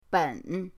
ben3.mp3